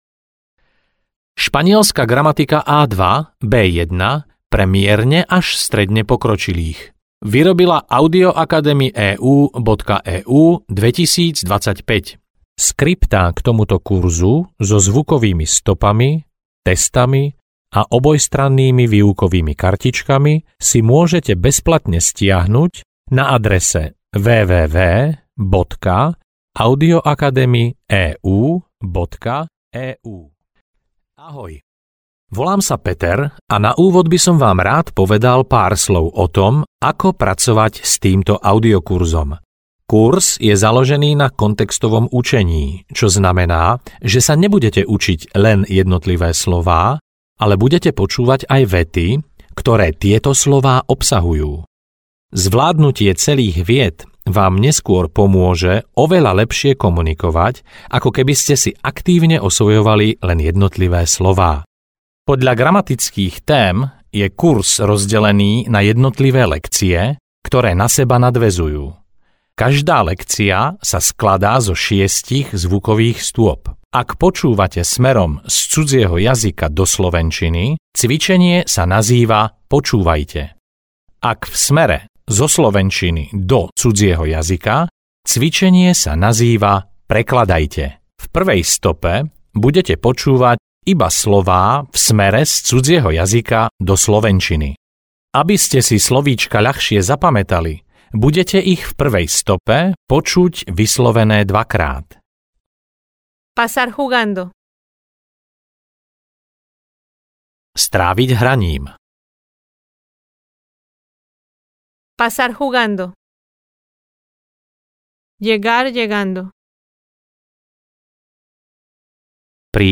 Španielska gramatika pre mierne pokročilých A2-B1 audiokniha
Ukázka z knihy